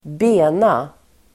Uttal: [²b'e:na]